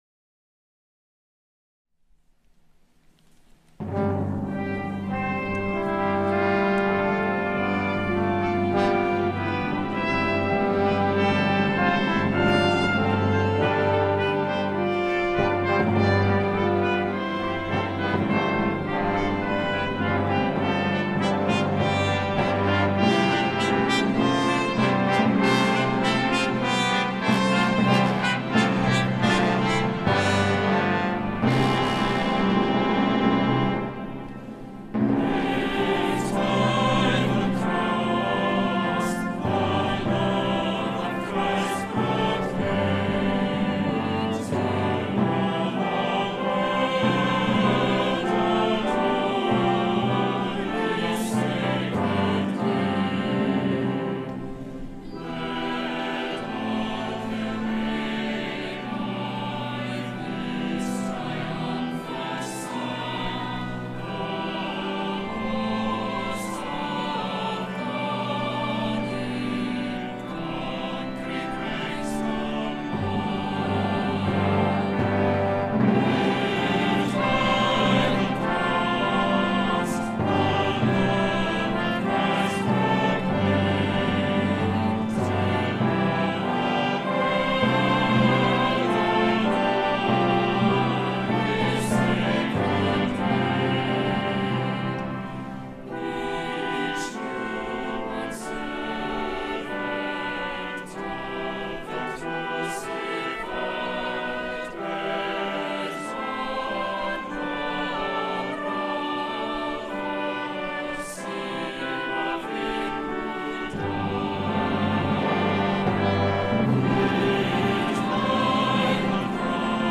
Voicing: Brass Quartet, Percussion and Organ